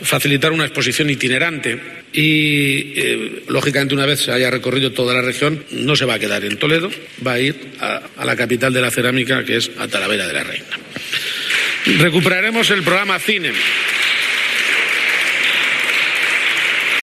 Lo ha anunciado el Presidente Page durante el Debate sobre el Estado de la Región
Tal como puedes escuchar en las declaraciones del Presidente (pinchando en la foto de portada) habrá una exposición itinerante que recorrerá la región y cuando termine la colección se quedará en Talavera de la Reina.